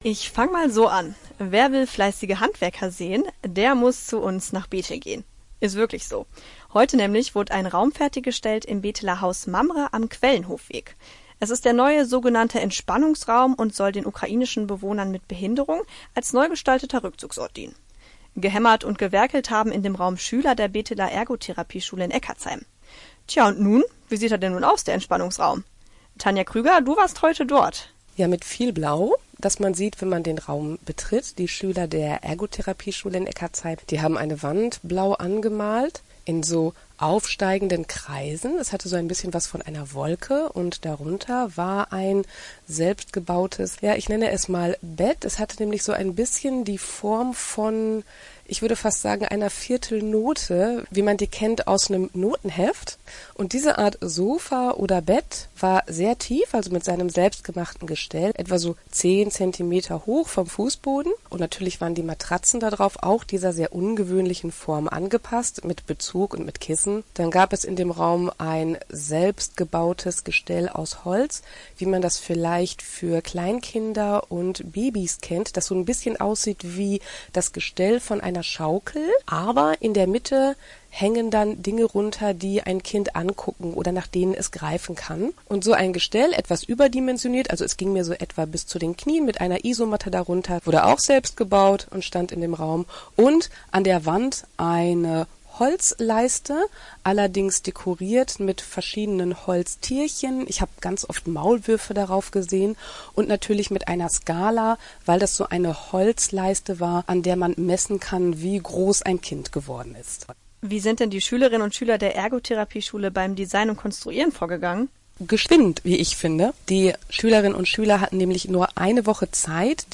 – Radio für Bethel, Gadderbaum und Eckardtsheim